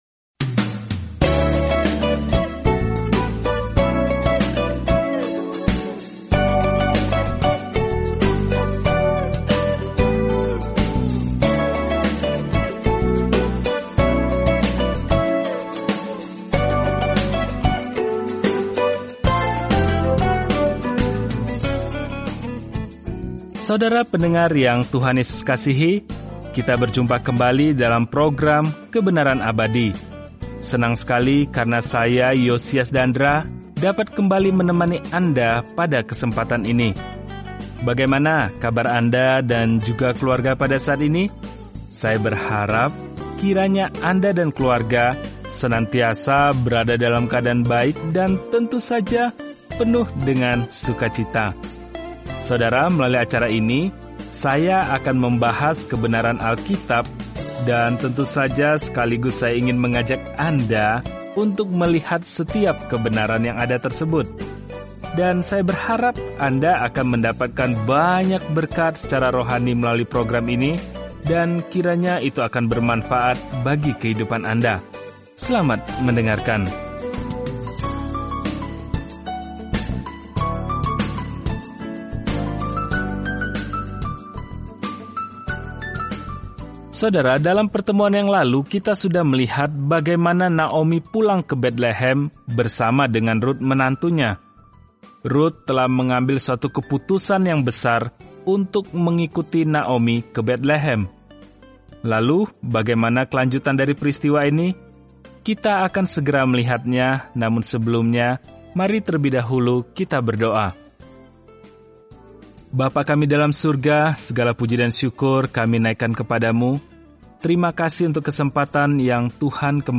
Firman Tuhan, Alkitab Rut 2:1-10 Hari 2 Mulai Rencana ini Hari 4 Tentang Rencana ini Ruth, sebuah kisah cinta yang mencerminkan kasih Tuhan kepada kita, menggambarkan pandangan sejarah yang panjang – termasuk kisah raja Daud… dan bahkan latar belakang Yesus. Jelajahi Rut setiap hari sambil mendengarkan pelajaran audio dan membaca ayat-ayat tertentu dari firman Tuhan.